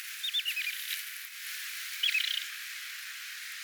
nuori ruokokerttunen,
pieni ääntely
tuolla_tavoin_ilm_nuori_ruokokerttunen_kommentoi_lintuharrastajan_nakemista.mp3